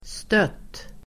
Uttal: [stöt:]